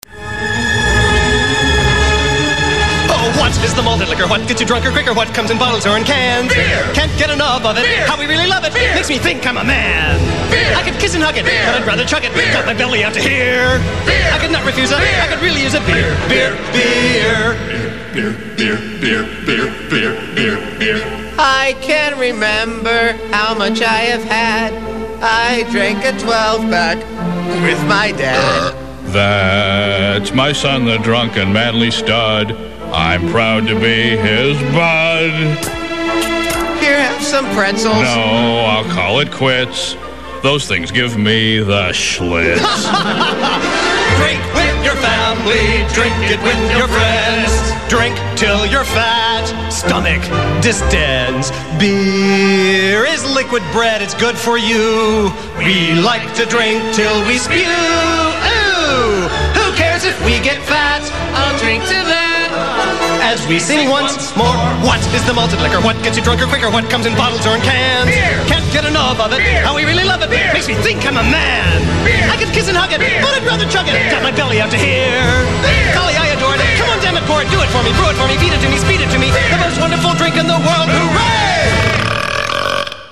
Just for those who think they sing to fast, here is the lyric